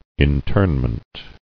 [in·tern·ment]